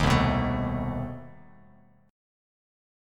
CmM13 chord